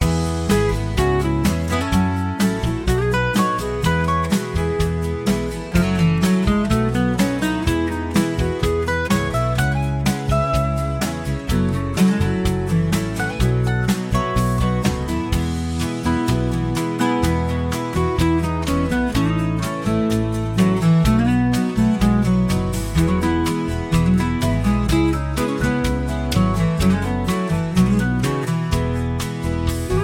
two instrumentals